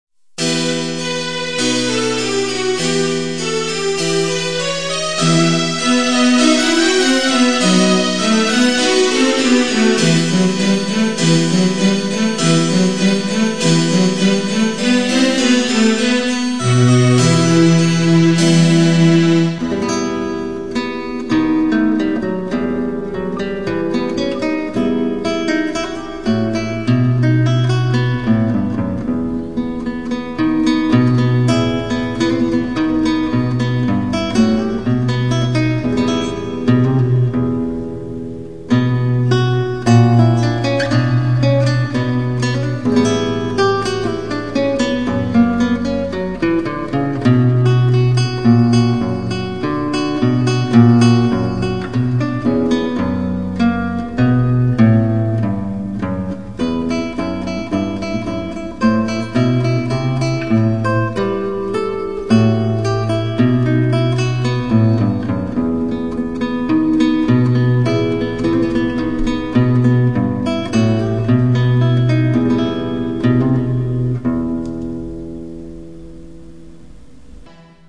Here you will find some mp3 samples with guitar recordings and other music.
This is the first piece of guitar music I recorded and post-processed on the computer.
That time I had a bad computer so the results aren't best. The piece is a Partita from 16th century, written for the lute (those times the guitar didn't even exists). I made the orchestral arrangements in the MIDI technique using the computer.